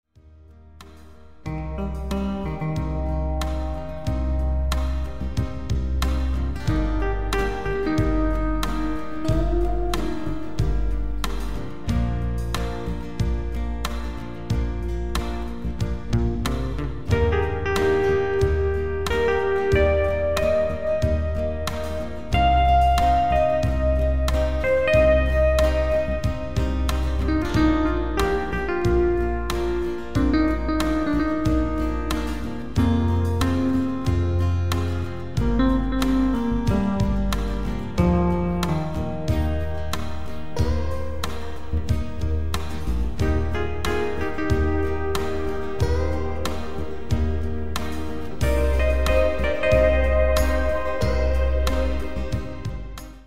Sie sind zum träumen und entspannen gedacht.
auch wieder ein Song nur auf dem Keyboard entwickelt.